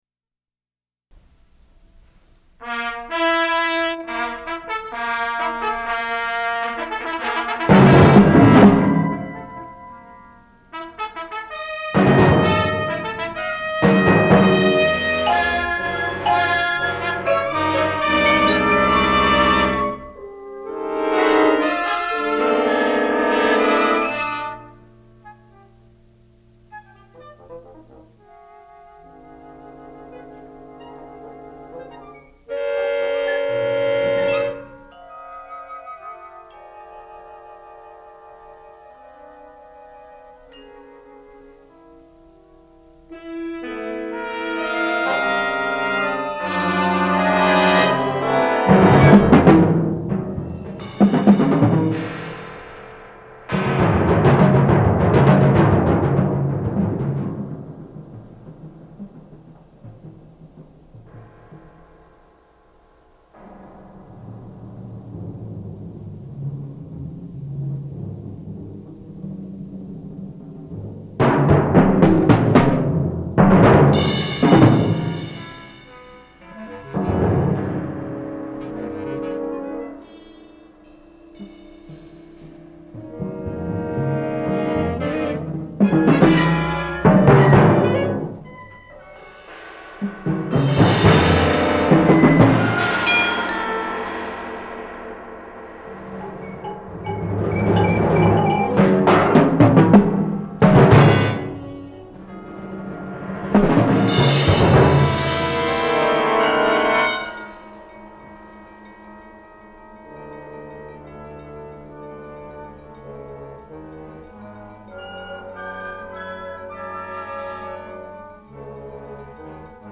picc/4 fl/2 ob/Eb cl/8 Bb cl/Bb bs cl/Eb bs cl/2bsn/
2 Eb alto sax/ Bb ten sax/
Eb bar sax/8 Bb tpt/ 4 hn/
4 tbn/2 euph/2 tba/
4 perc/piano